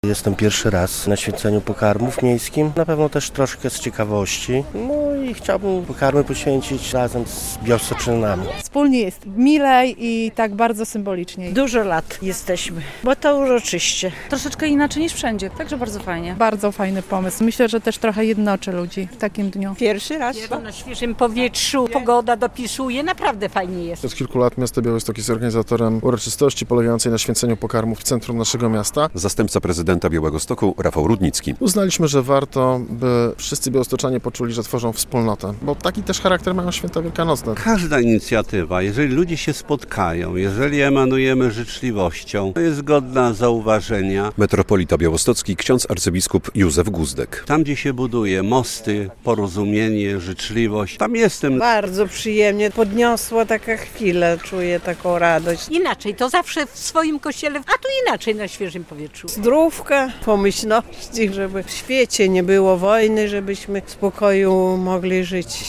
Miejskie święcenie pokarmów na Rynku Kościuszki